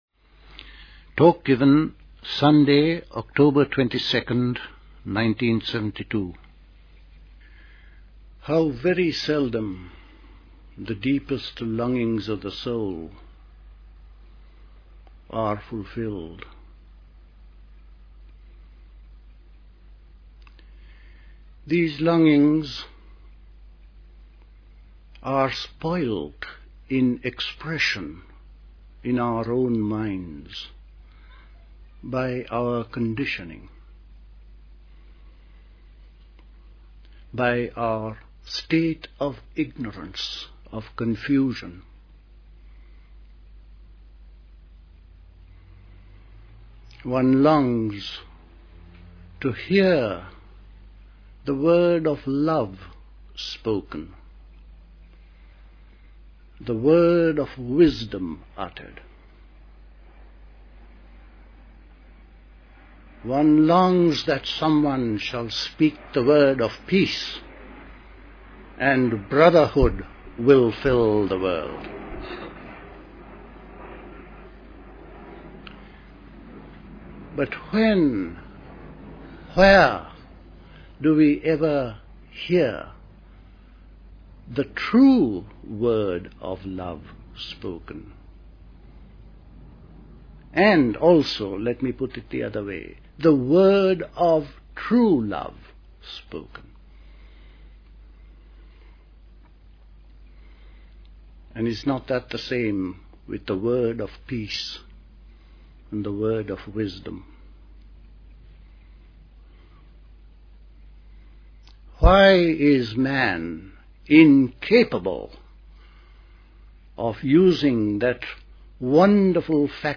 at Dilkusha, Forest Hill, London on 22nd October 1972